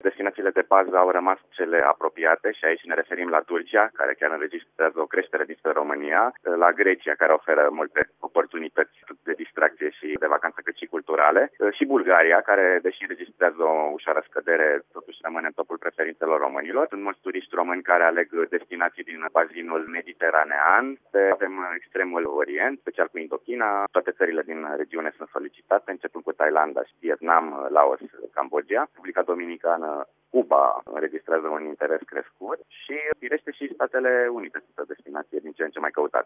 spune consultantul în turism